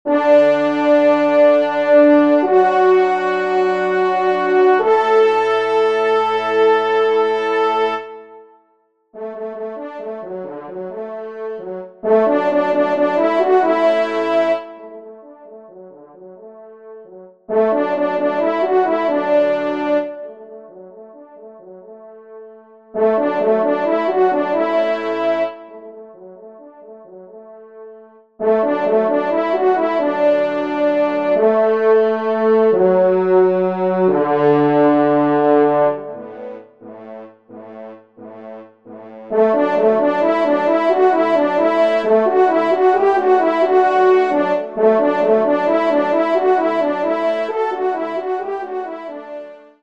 Genre : Divertissement pour Trompes ou Cors
Pupitre 1° Cor